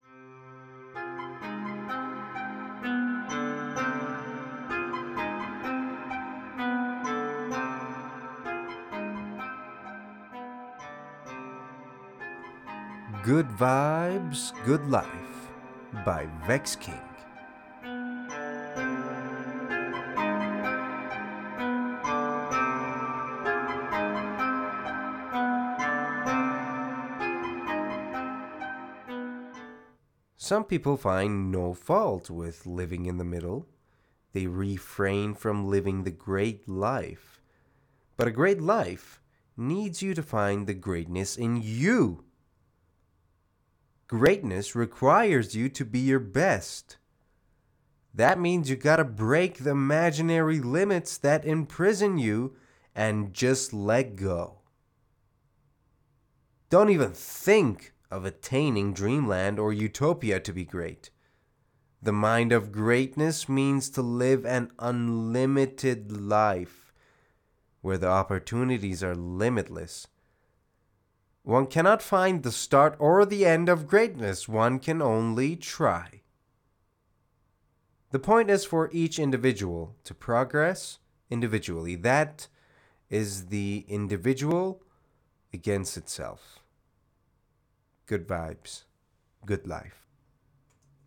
معرفی صوتی کتاب Good Vibes Good Life